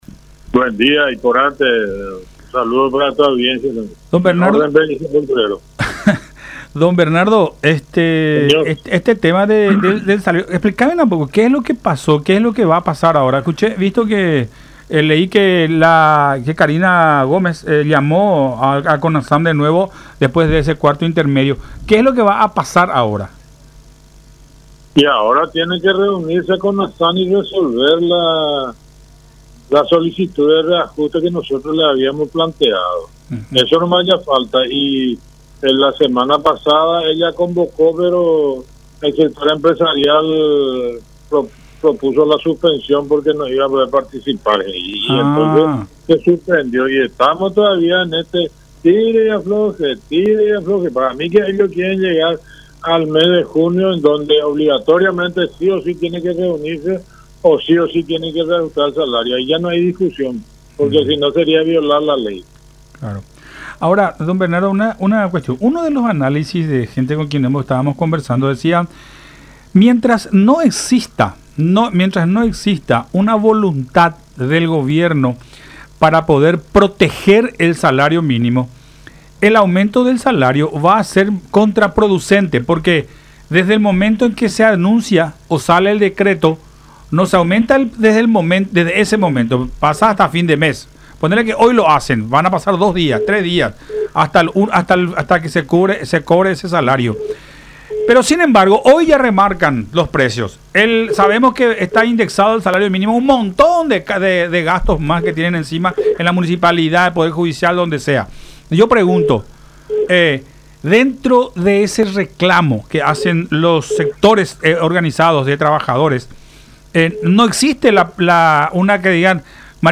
en conversación con Todas Las Voces por La Unión